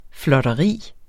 Udtale [ flʌdʌˈʁiˀ ]